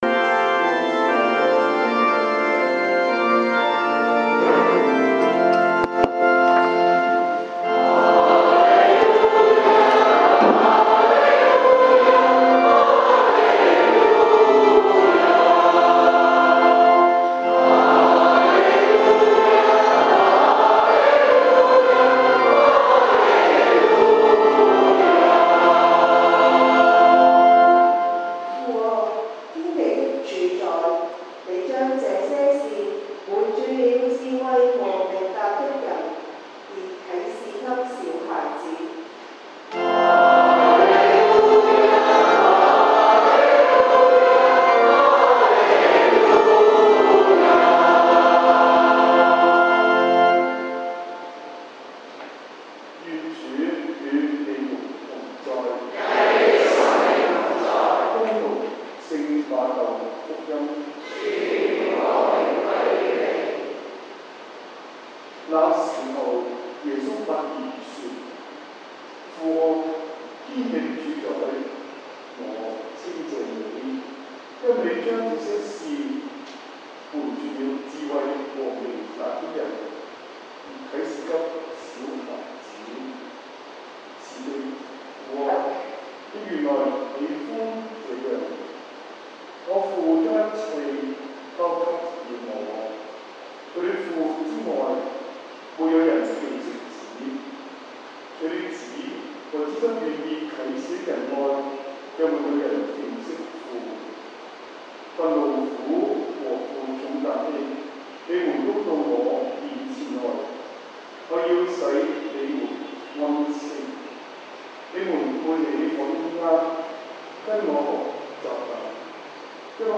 7:15am, 英文講道